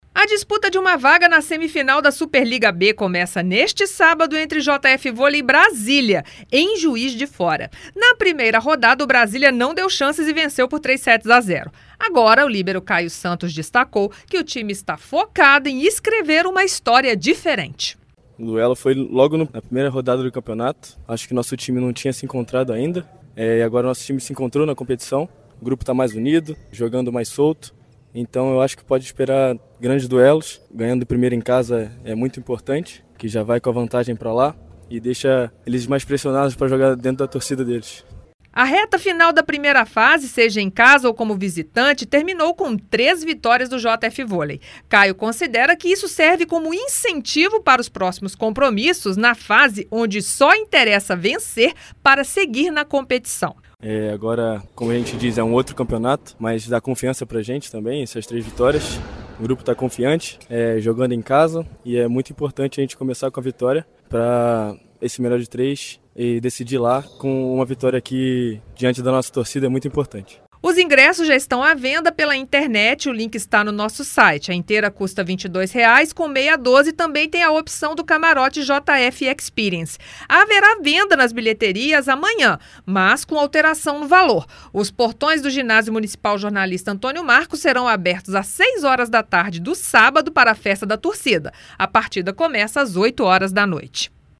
em entrevistas